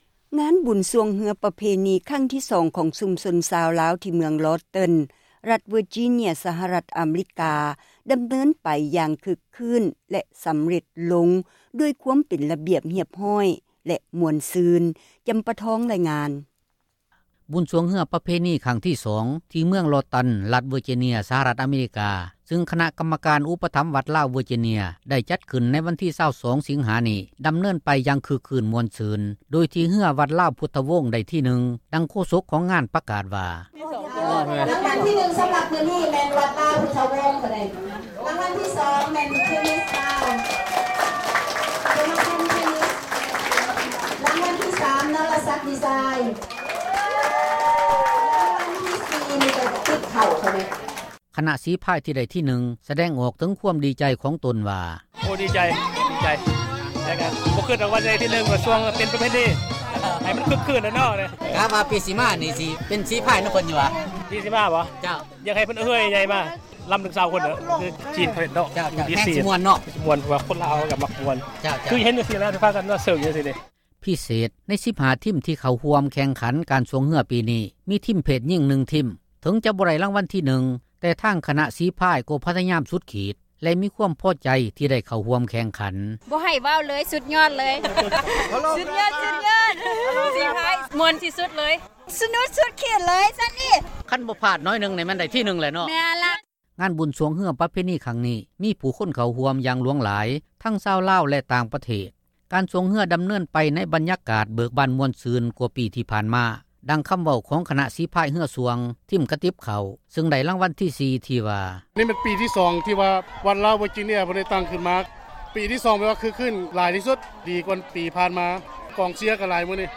ຄນະ ສີພາຍ ທີ່ໄດ້ ທີ 1 ສະແດງອອກ ເຖິງຄວາມ ດີໃຈ ຂອງຕົນວ່າ: